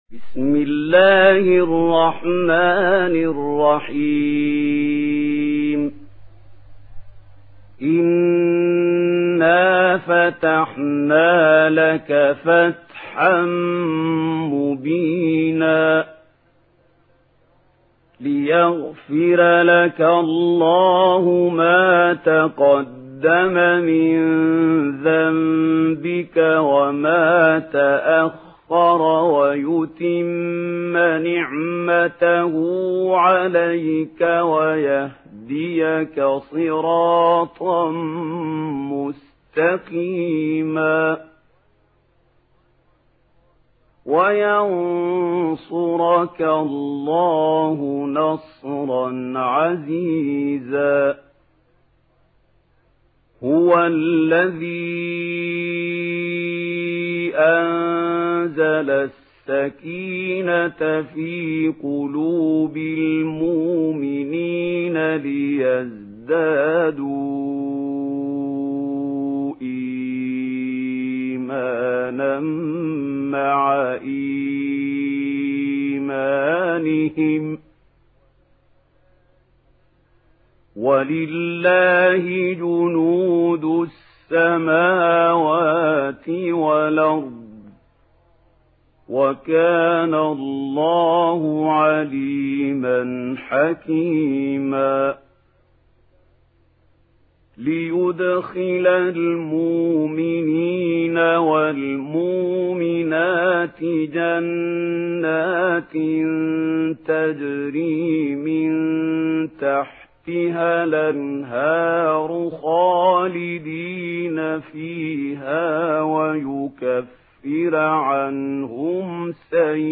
Surah Al-Fath MP3 in the Voice of Mahmoud Khalil Al-Hussary in Warsh Narration
Listen and download the full recitation in MP3 format via direct and fast links in multiple qualities to your mobile phone.